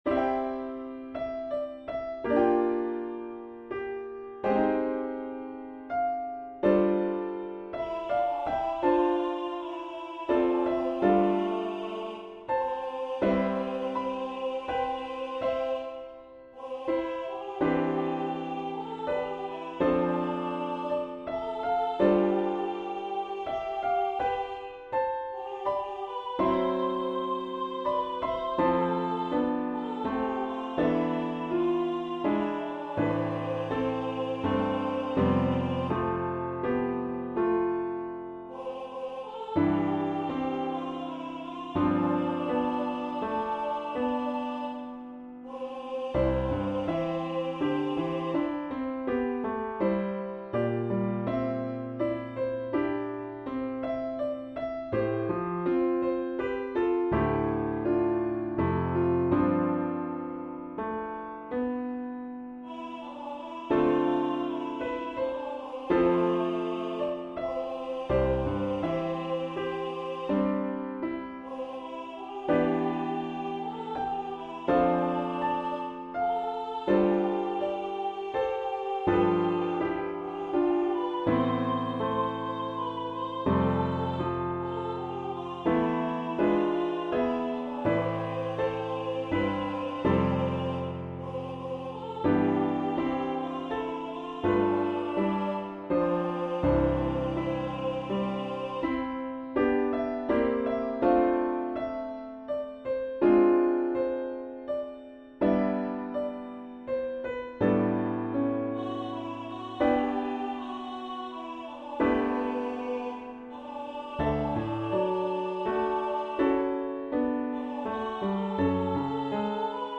Medium Voice/Low Voice